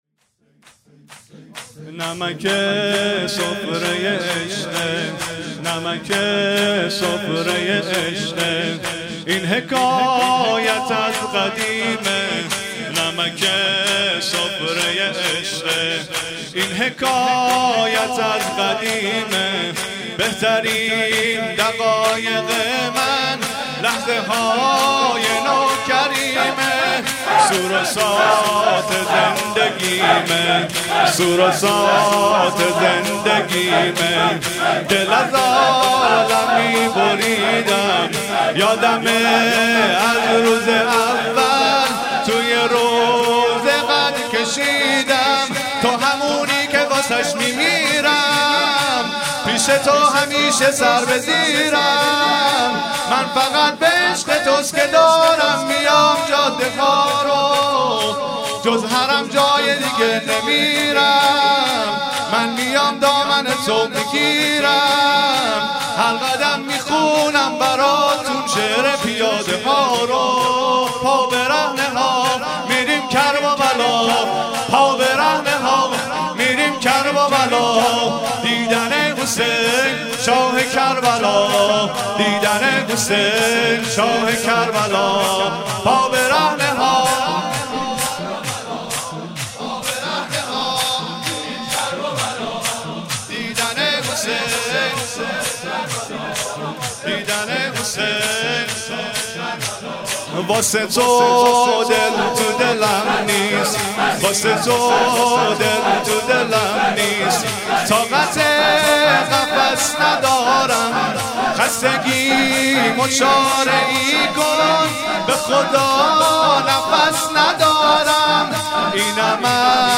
شور ترکیبی
بیت العباس اراک